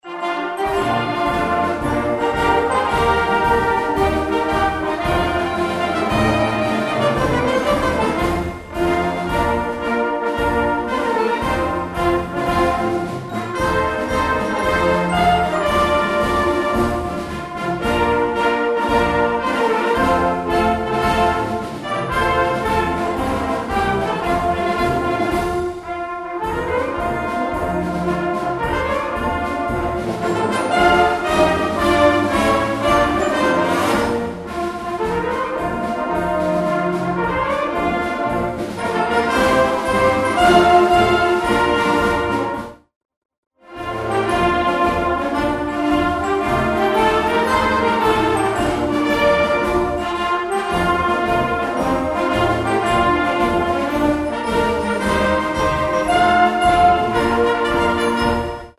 einen echten 'Niederländischen' Marsch
Schwierigkeit C - durchschnitt